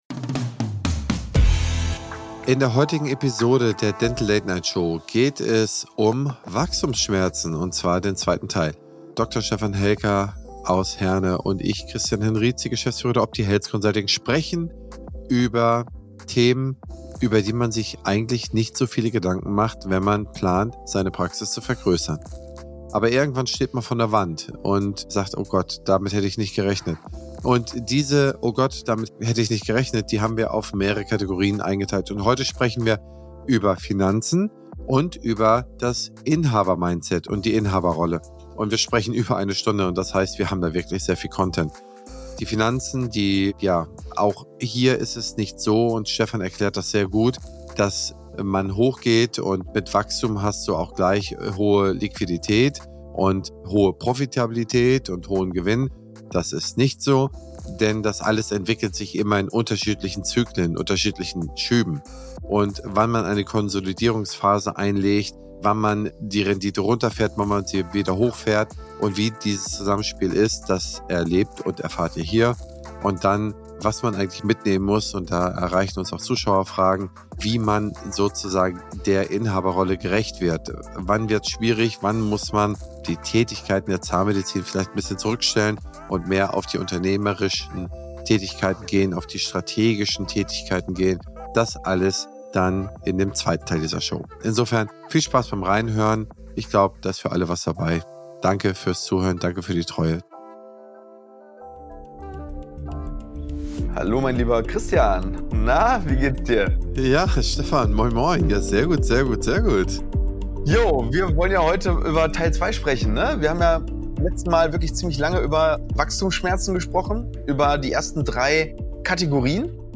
Heute gibt es den zweiten Teil von "Wachstumsschmerzen", einer Aufzeichnung der Dental Late Night Show.